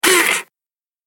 دانلود آهنگ دوربین 11 از افکت صوتی اشیاء
دانلود صدای دوربین 11 از ساعد نیوز با لینک مستقیم و کیفیت بالا
جلوه های صوتی